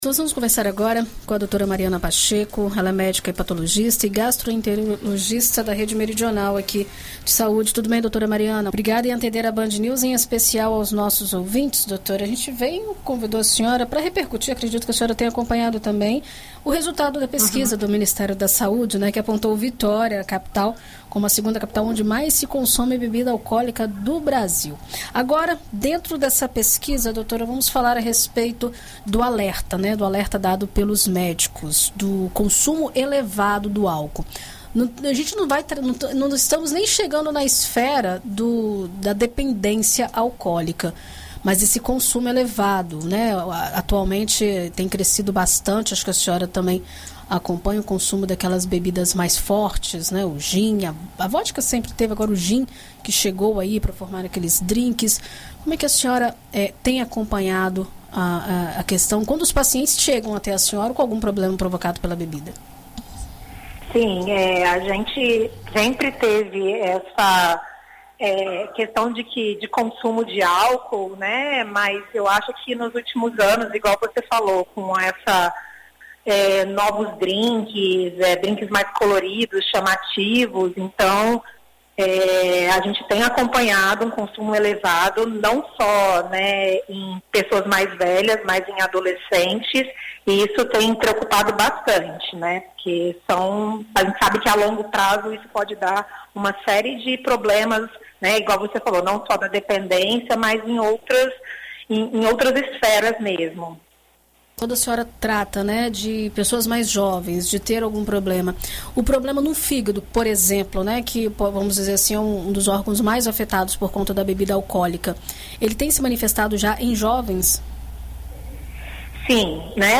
ENT-HEPATOLOGISTA-CONSUMO-ÁLCOOL.mp3